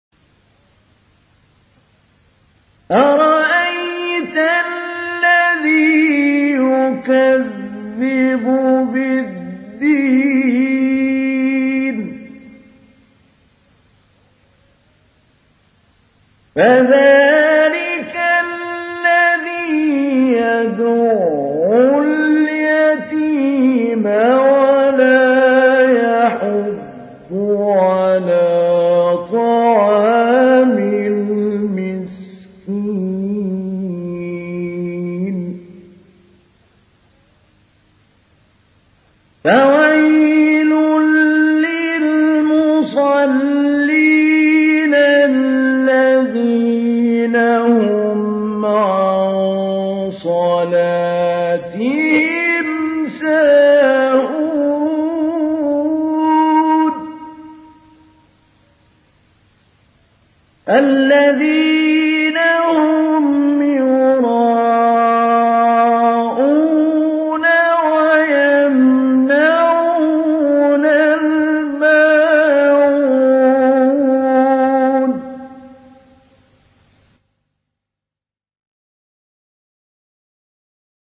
Surah Repeating تكرار السورة Download Surah حمّل السورة Reciting Mujawwadah Audio for 107. Surah Al-M�'�n سورة الماعون N.B *Surah Includes Al-Basmalah Reciters Sequents تتابع التلاوات Reciters Repeats تكرار التلاوات